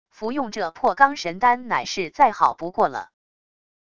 服用这破罡神丹乃是再好不过了wav音频生成系统WAV Audio Player